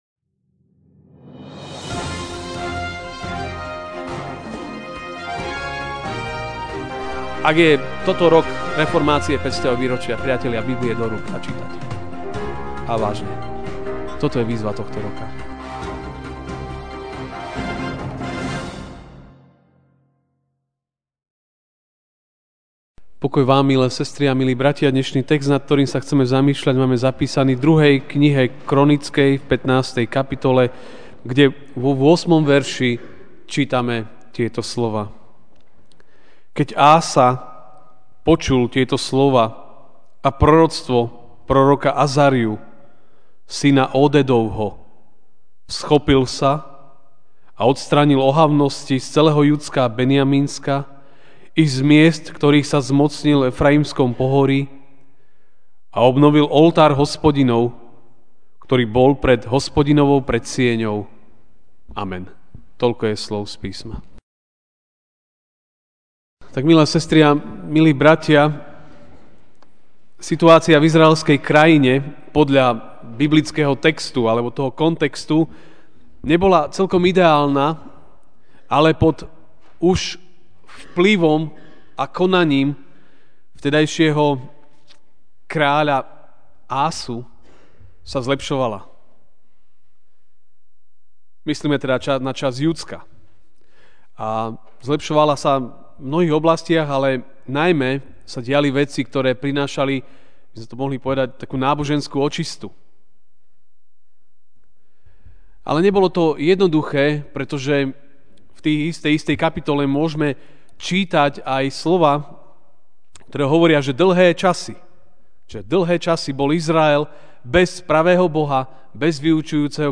Ranná kázeň: Boh hovorí (2. Kron. 15, 8) Keď Ása počul tieto slová a proroctvo proroka Azarju, syna Ódédovho, vzchopil sa a odstránil ohavnosti z celého Judska a Benjamínska i z miest, ktorých sa zmocnil v Efrajimskom pohorí, a obnovil oltár Hospodinov, ktorý bol pred Hospodinovou predsieňou.